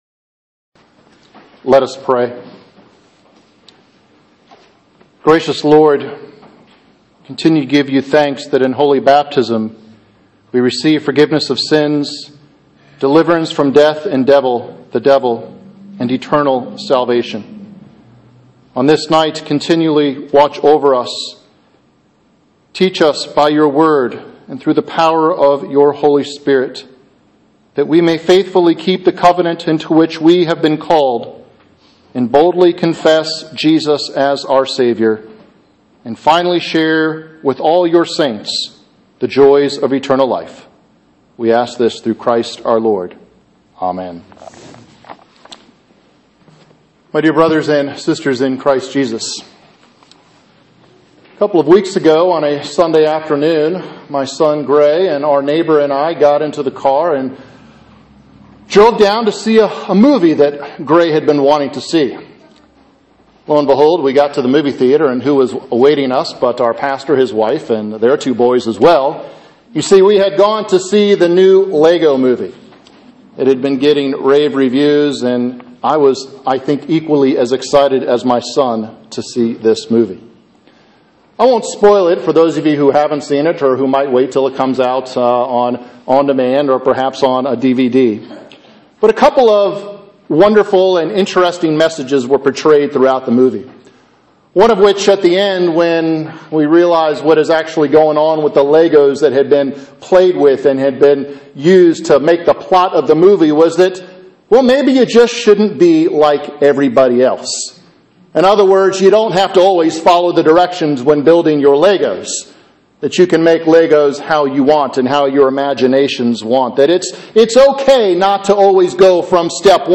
Sermon: Lent 1 Midweek Matthew 28:16-20